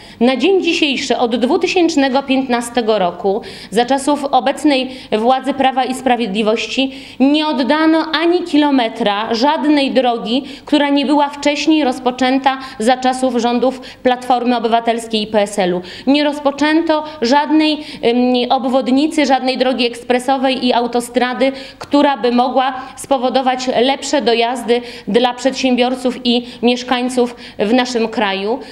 Zaniedbania w budowie dróg oraz planowana Rail Baltica były tematem konferencji prasowej, którą zorganizowała w piątek (11.10.19), w Suwałkach, Bożena Kamińska, posłanka Platformy Obywatelskiej. Zdaniem posłanki, rząd Prawa i Sprawiedliwości, w kwestii dróg, dokończył jedynie plany poprzedniego rządu.